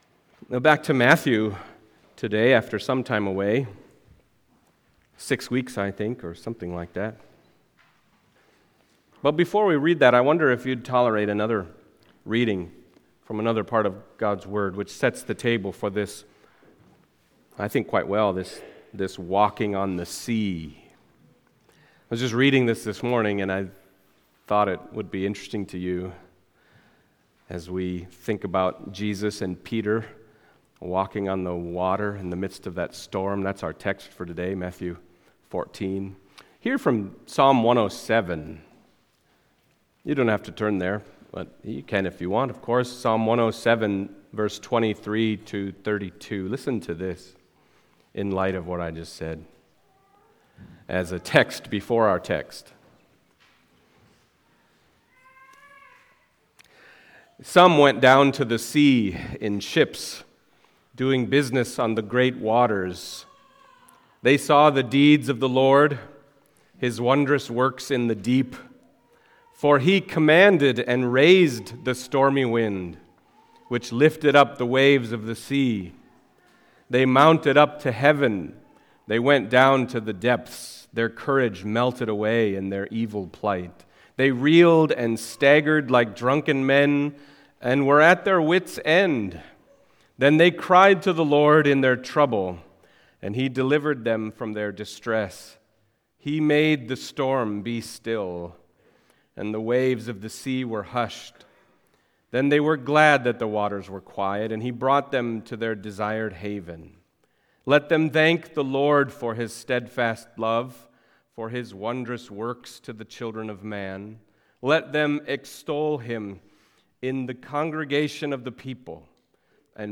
Matthew Passage: Matthew 14:22-36 Service Type: Sunday Morning Matthew 14:22-36 « You Must Be Born Again